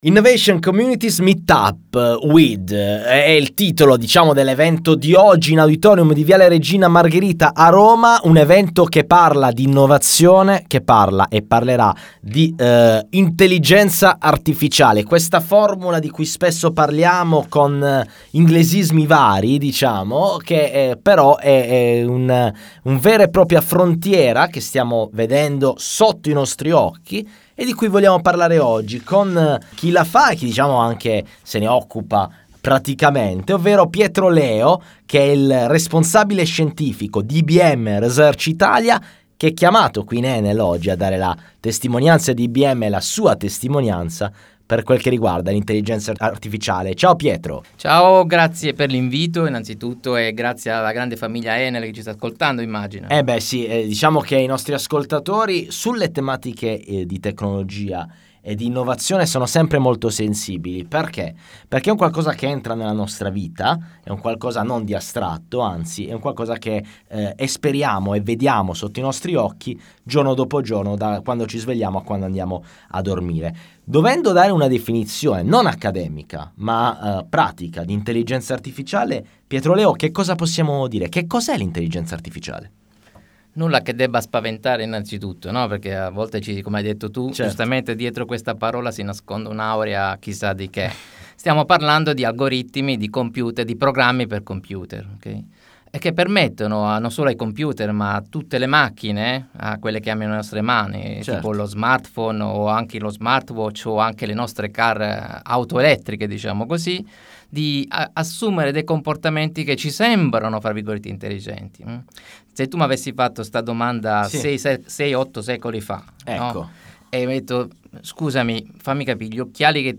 Interview by “Radio ENEL”: cos’è l’intelligenza artificiale?https